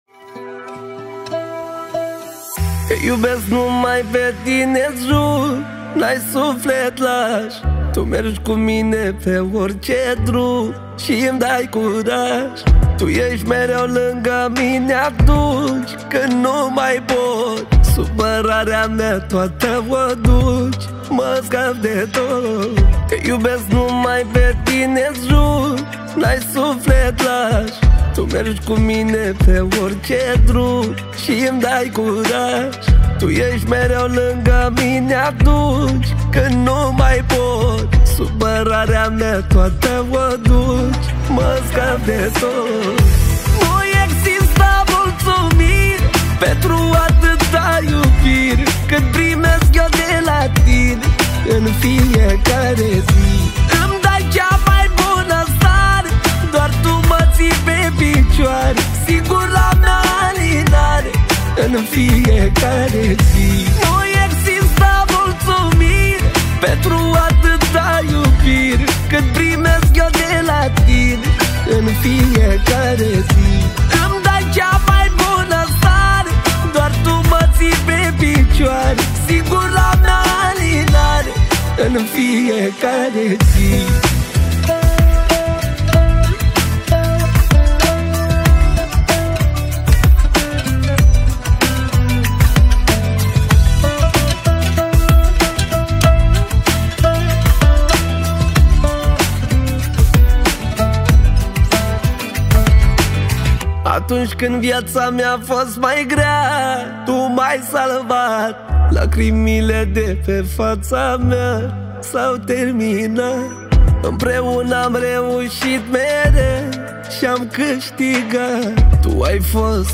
Data: 05.10.2024  Manele New-Live Hits: 0